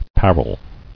[par·rel]